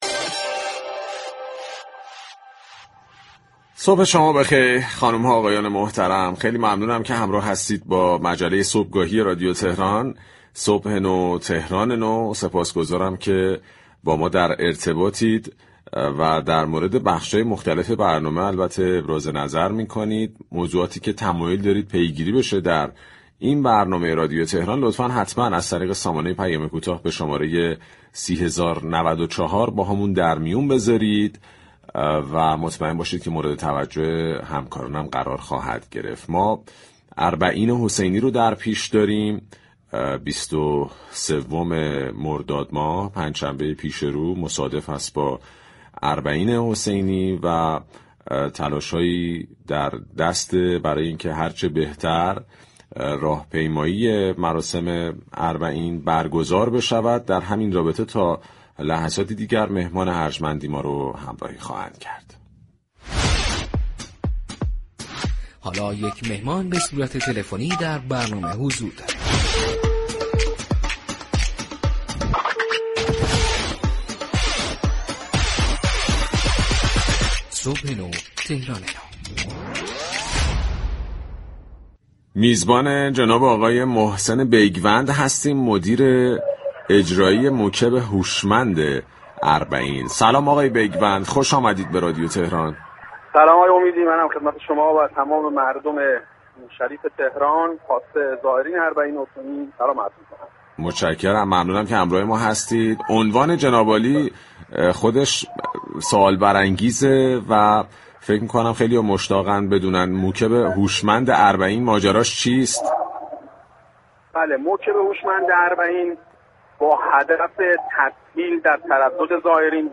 وی در مصاحبه با رادیو تهران، از امكان دریافت ارز رایگان اربعین در صورت خرید از كیف پول الكترونیكی در طول سال از برخی فروشگاه‌ها خبر داد. این طرح به صورت پایلوت در كاشان اجرا شده و تعدادی از زائران توانستند كه امسال برای زیارت اربعین دینار رایگان دریافت كنند.